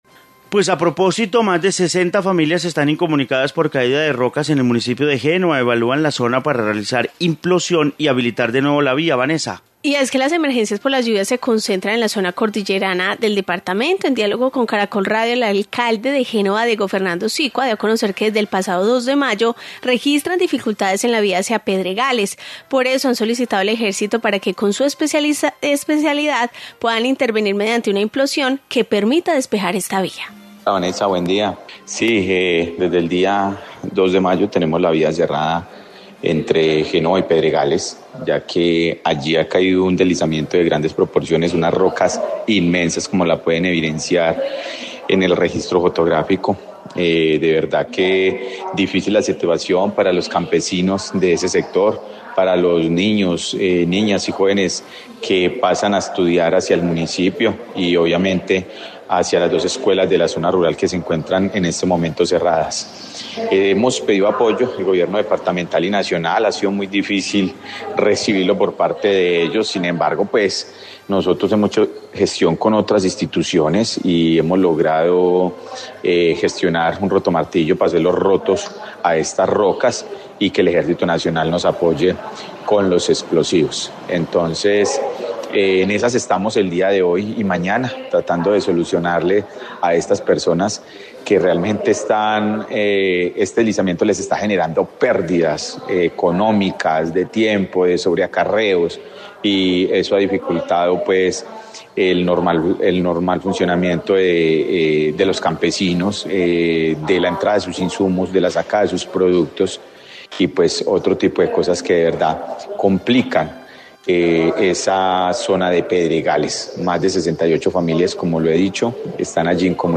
Informe lluvias Génova